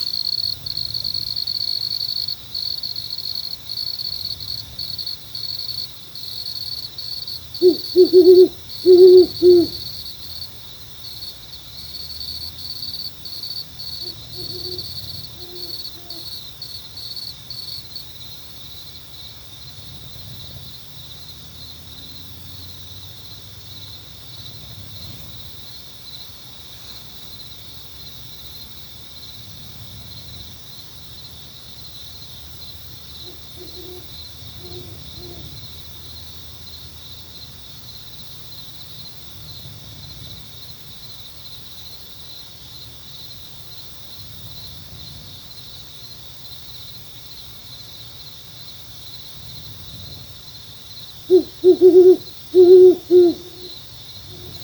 Great Horned Owl – Territorial Call – Flying Squirrel Outdoors
During the weekend camping and sailing last week, we had an early wakeup call at 4am, when a Great Horned Owl set up shop in a tree directly over my tent, and started making territorial declarations in a duet with another owl about 200 yards away across an inlet.  At first I just smiled and ignored it, but eventually I mustered the energy to roll over and hit “record” on my phone.  You can hear both owls.
great-horned-owl-territorial-tall-timbers.mp3